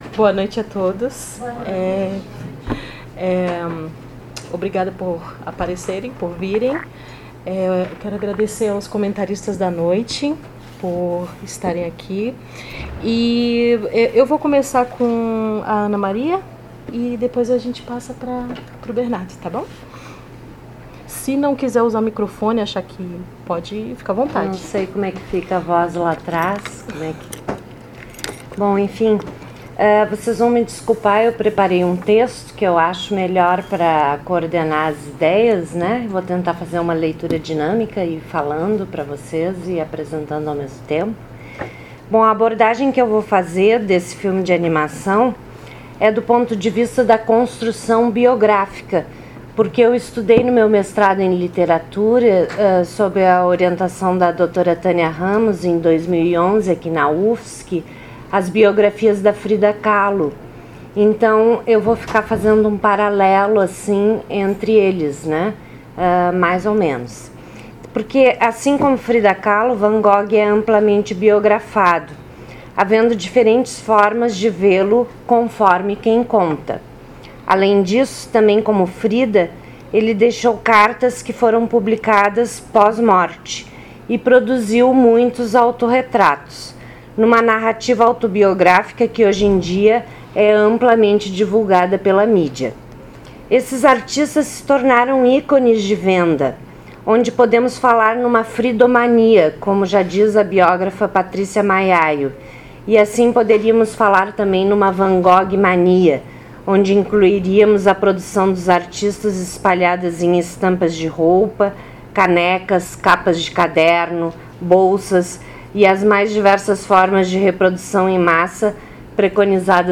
realizada em 04 de abril de 2019 no Auditório "Elke Hering" da Biblioteca Central da UFSC